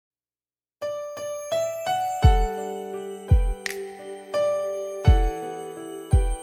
Reach Out. (AC/pop)